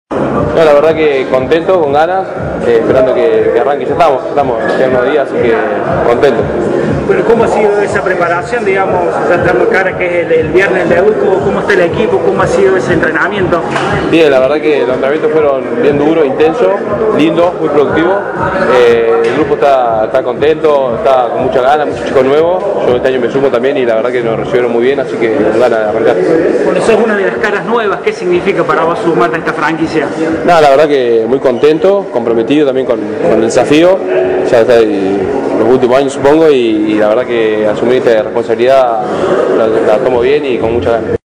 En la presentación de Los Dogos en el estadio Mario Kempes, antes del debut con Cobras de Brasil